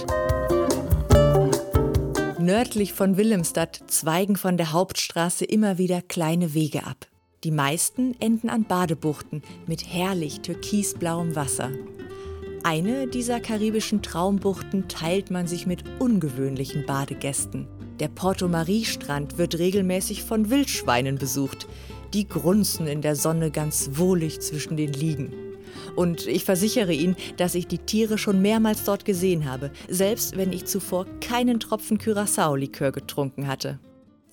Kreuzfahrt Karibik. Das Hörbuch zur Karibikreise. Mit Fotobuch (USB-Stick)